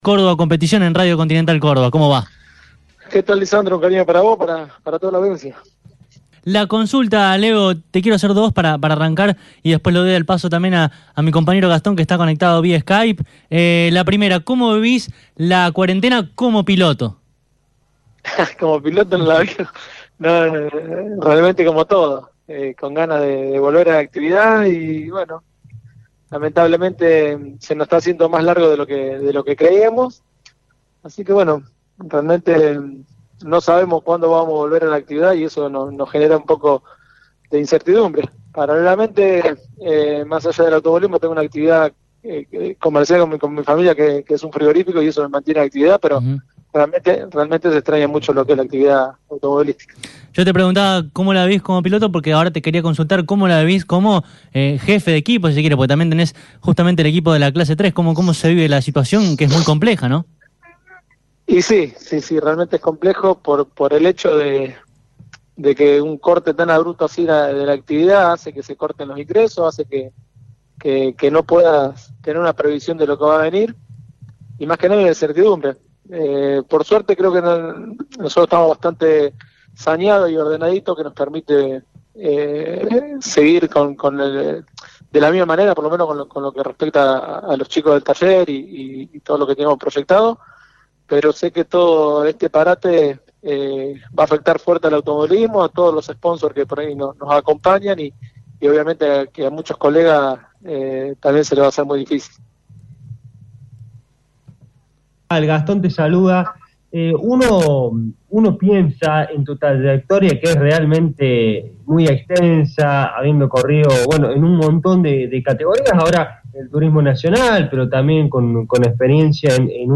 A continuación podés escuchar la entrevista completa: